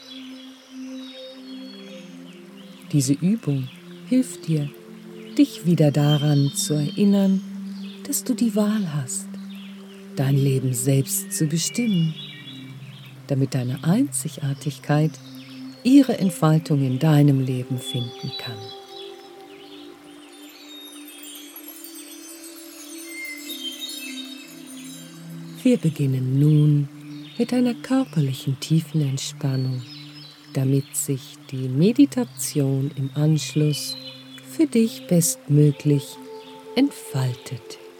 Die sprachliche Führung, ein tragendes Sound-Design und dreidimensionaleNaturgeräusche helfen Ihnen, Ihren Alltag für die Zeit der Übung hinter sich zu lassen.
Durch die fein abgestimmte Kombination aus echten Naturklängen und heilsamen sphärischen Melodien erreichen Sie eine Veränderung der wissenschaftlich belegten Schwingungen in Ihrem Gehirn - von Beta-Wellen (38-15 Hz) zu Alpha-Wellen (14-8 Hz) hin zu Theta-Wellen (7-4 Hz).